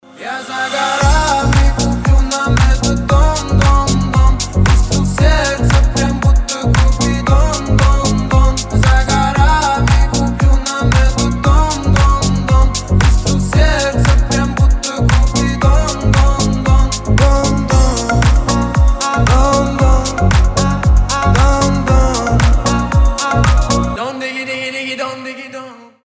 • Качество: 320, Stereo
ритмичные
громкие
Хип-хоп